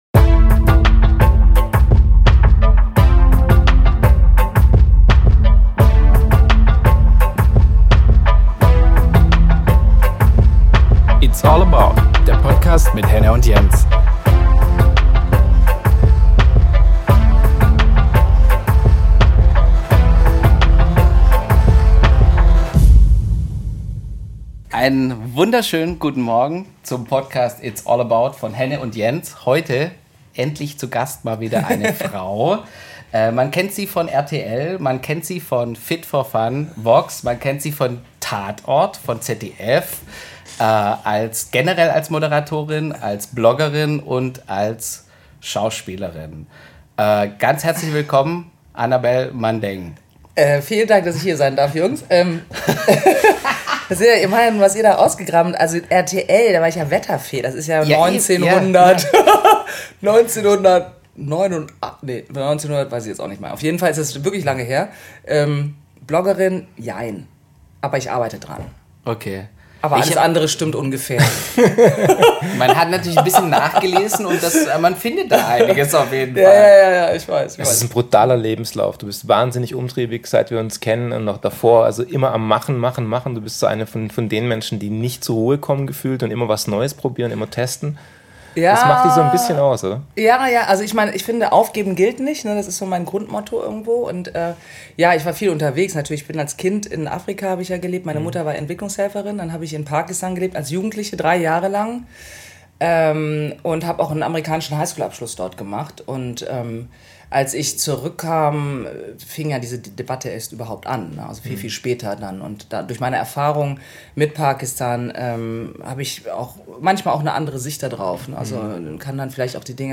'It's all about' im Gespräch